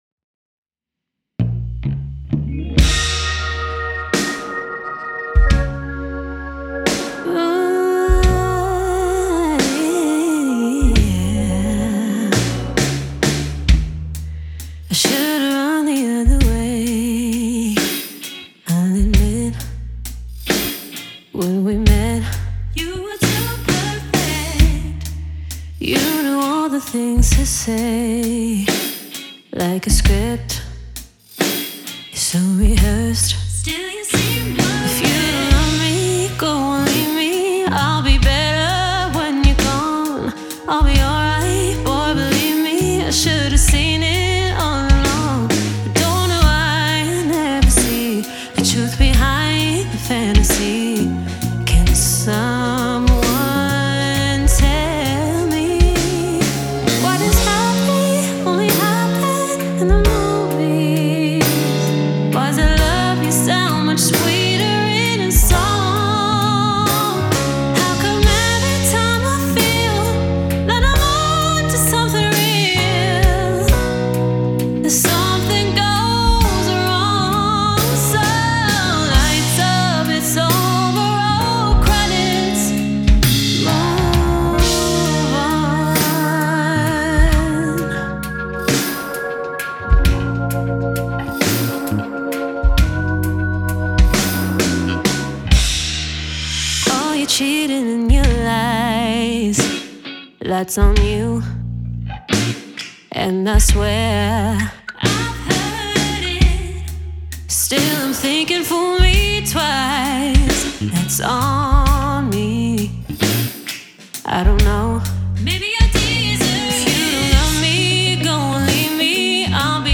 Drums
Bass
Guitar